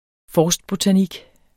Udtale [ ˈfɒːsdbotaˌnig ]